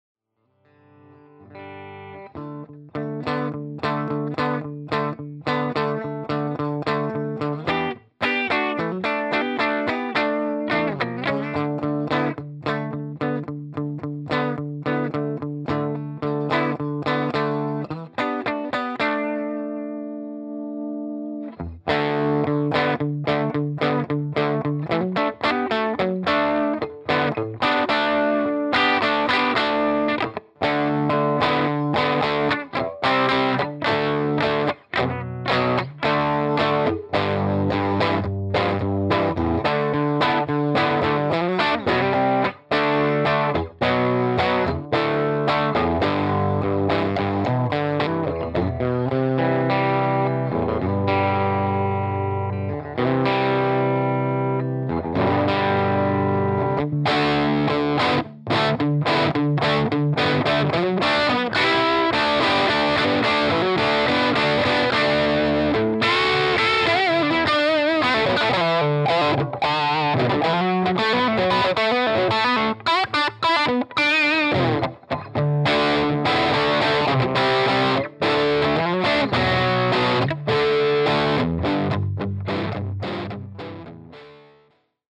2) Overdrive Boost.
The second part is the guitar with the bridge volume turned up and the neck volume on 7. The third part of the clip is the same settings with the BD2 engaged. It was set for minimal volume boost and medium gain.
I should be clear how much the BD2 compliments the overdrive tone of the deluxe.
BD2Pedal_deluxe_dirtyboost.mp3